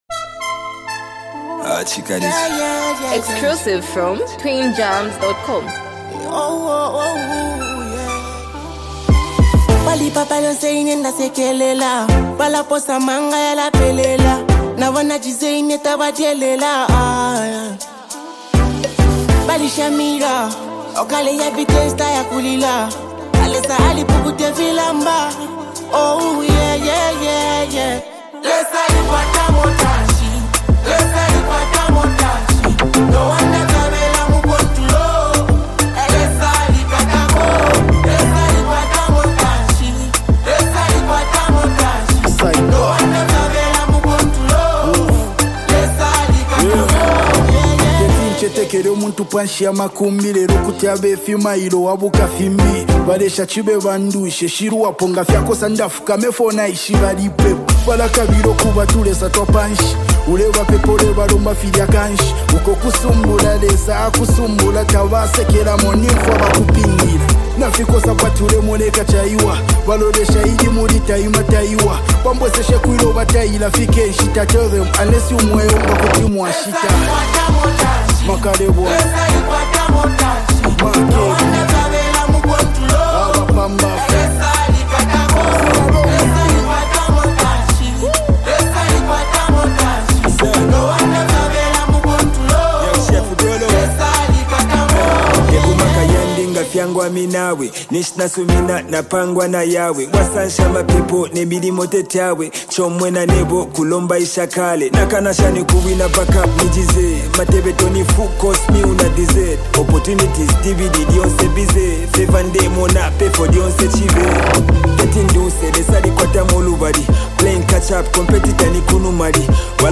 verse packed with clever wordplay and smooth flow